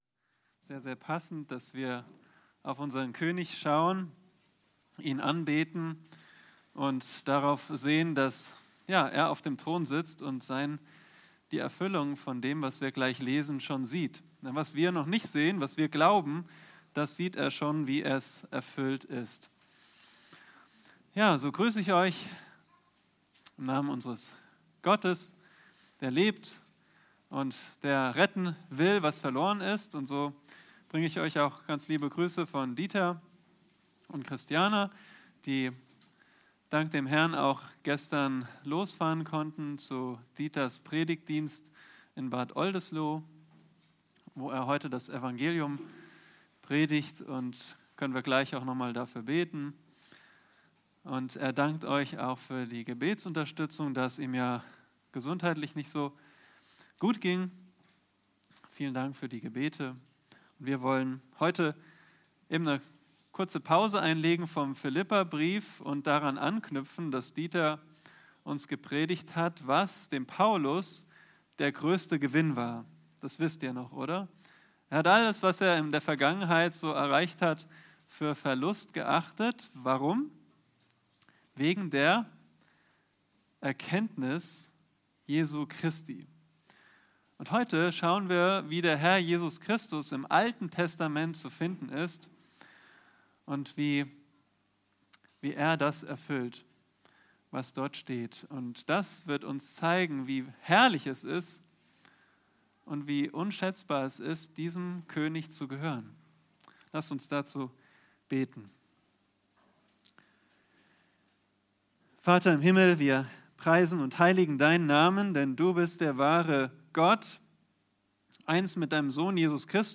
Predigten - Übersicht nach Serien - Bibelgemeinde Barnim